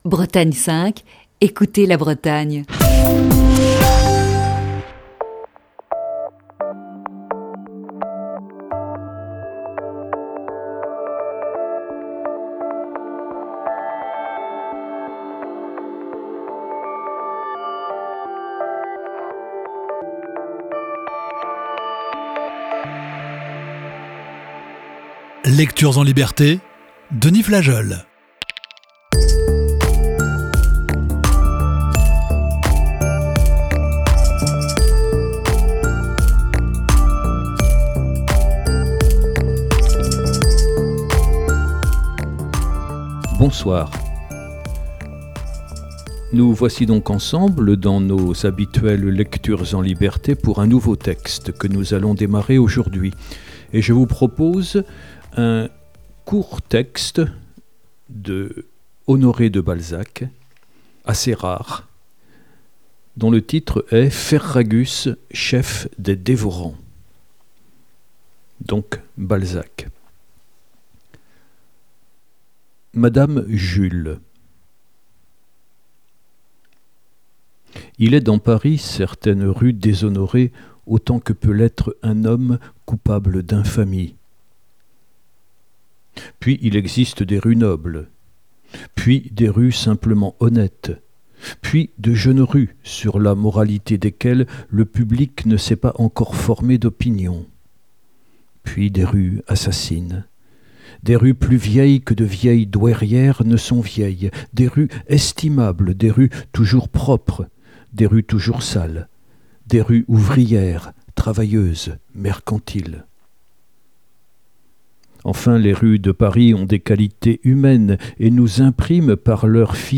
avec la lecture de "Ferragus